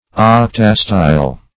Octastyle \Oc"ta*style\, a.